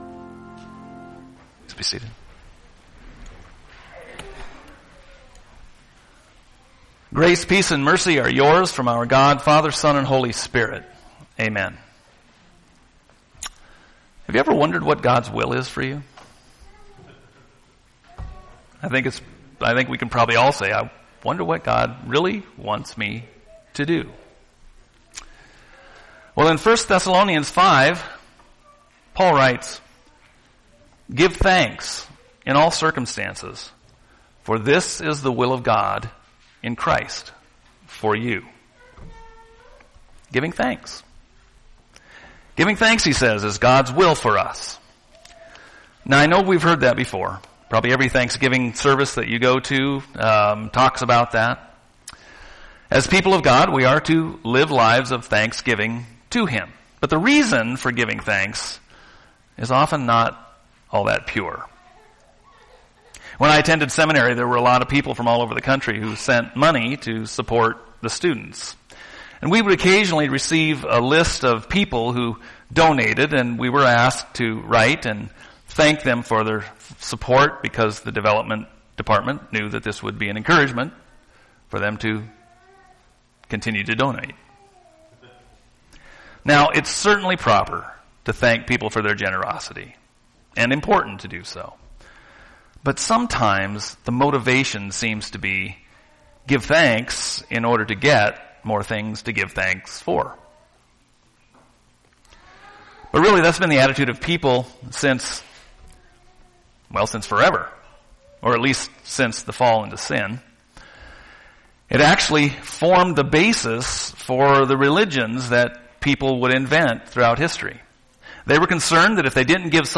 Sunday Service Sermons